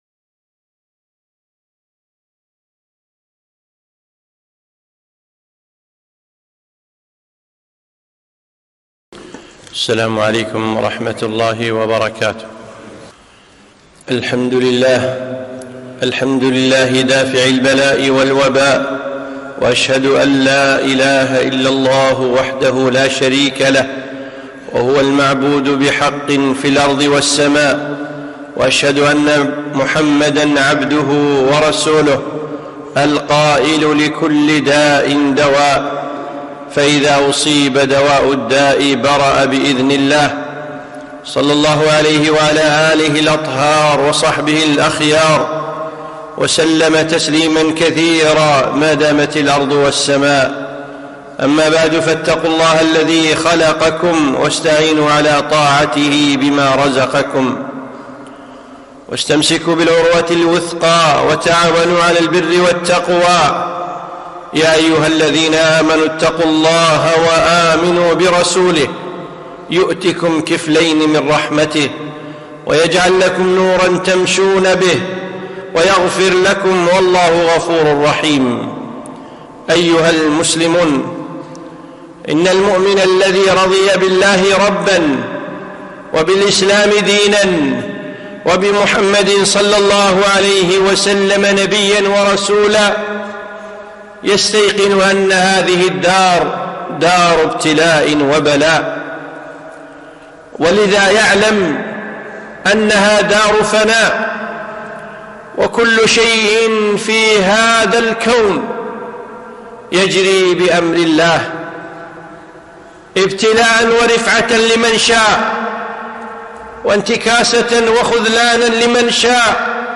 خطبة - الله الشافي